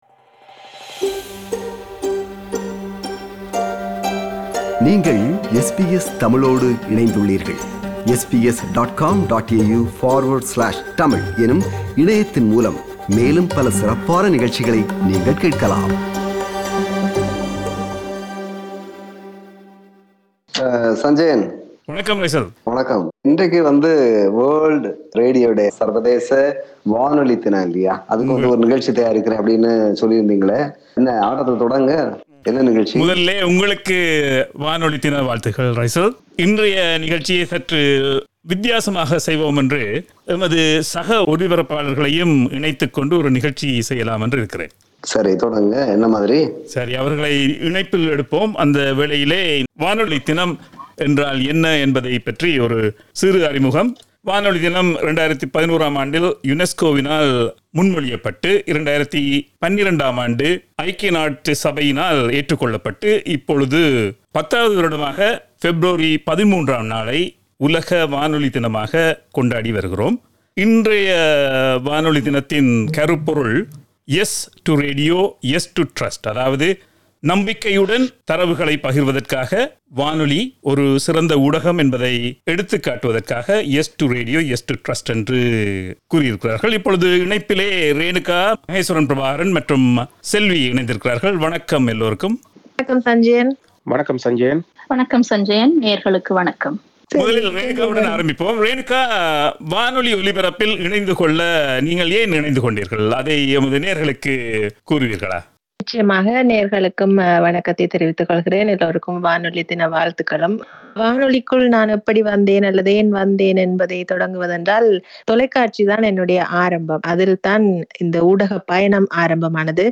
Yes, to Radio 15:20 SBS Tamil broadcasters celebrating World Radio Day.